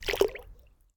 water-splash-08
bath bathroom bubble burp click drain dribble dripping sound effect free sound royalty free Nature